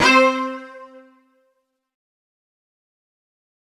Strings (4).wav